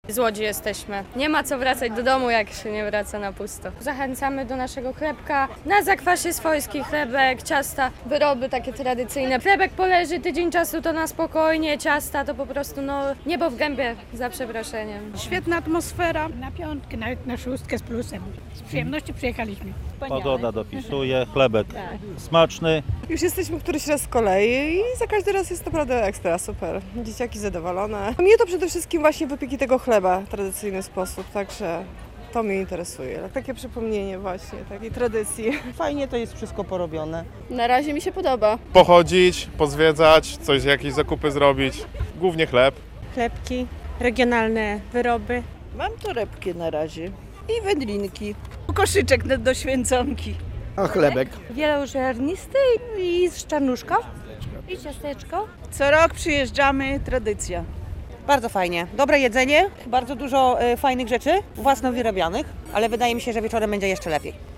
Wrażenia uczestników biorących udział w 22. Podlaskim Święcie Chleba w Muzeum Rolnictwa w Ciechanowcu - relacja